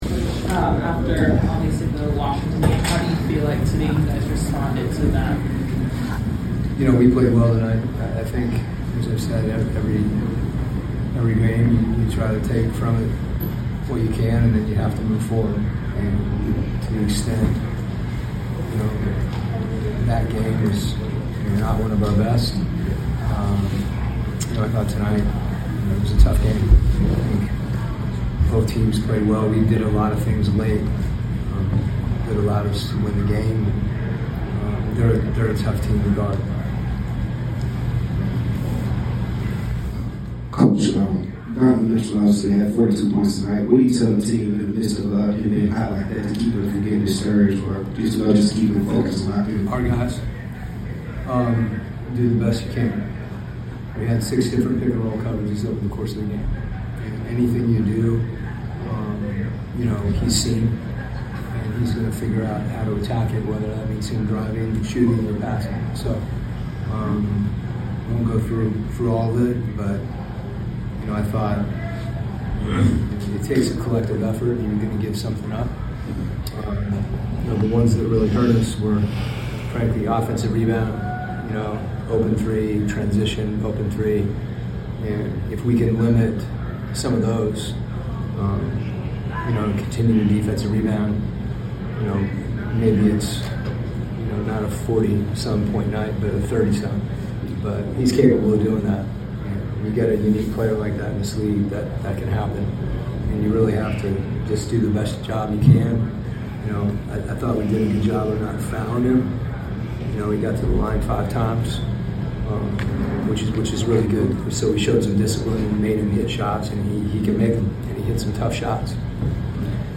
Atlanta Hawks Coach Quin Snyder Postgame Interview after defeating the Cleveland Cavaliers at State Farm Arena.